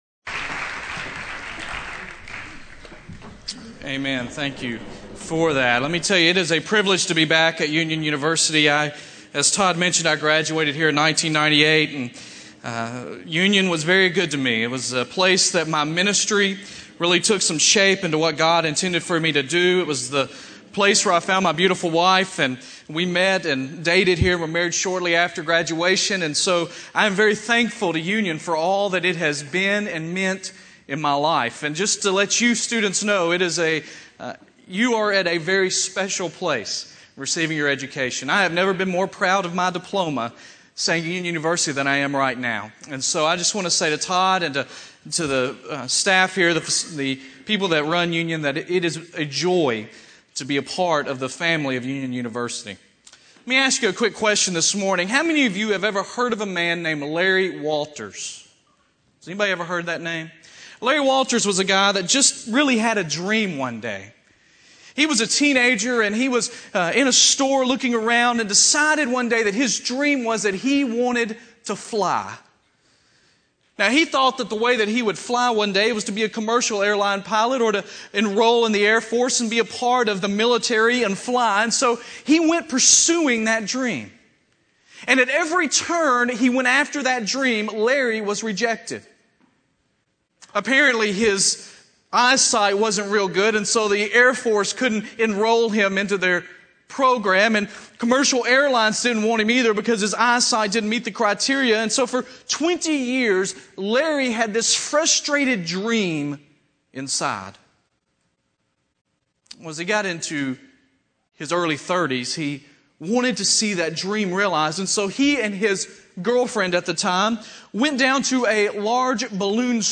Chapel
Address: "When Strange is Normal" from Acts 2:1-41 Recording Date: Apr 19, 2006, 10:00 a.m. Length: 27:45 Format(s): WindowsMedia Audio ; RealAudio ; MP3 ;